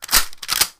shotgun_pump_1.wav